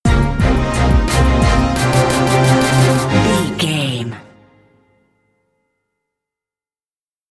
Fast paced
In-crescendo
Aeolian/Minor
B♭
strings
drums
horns